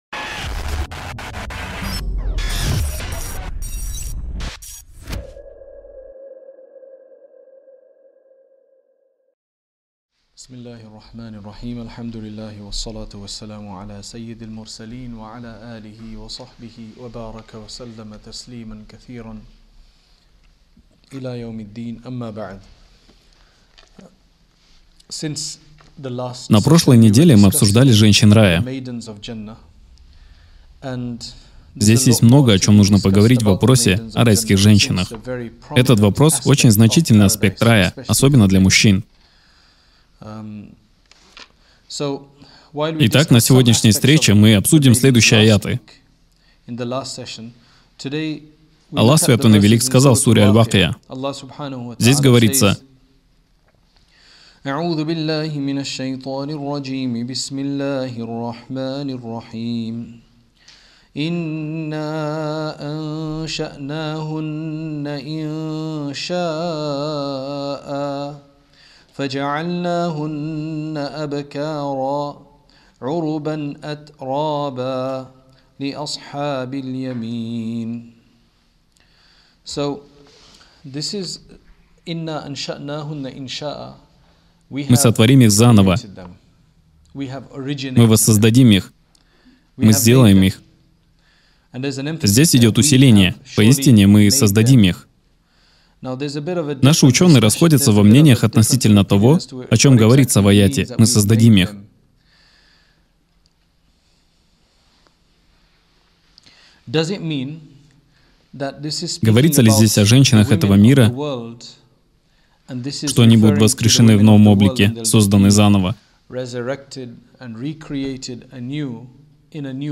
В данном цикле лекций о райских гуриях (хур аль-инн)